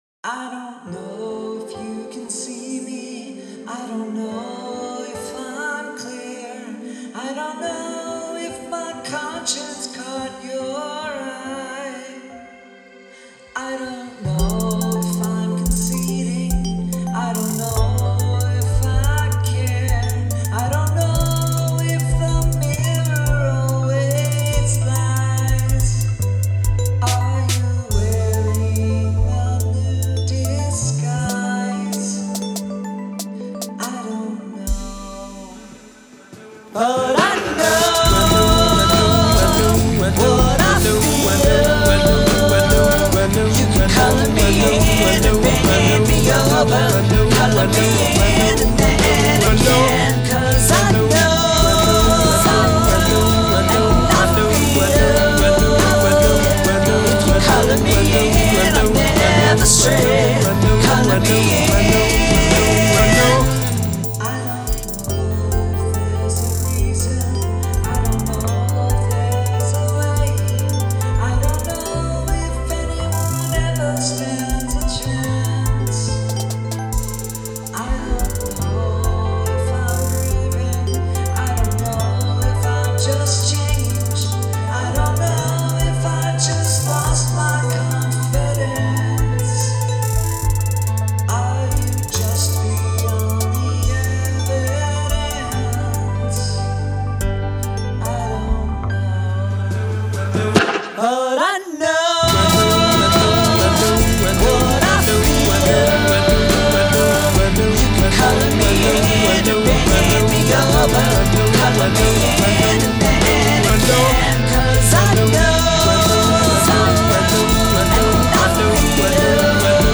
Sampled Vocal Hook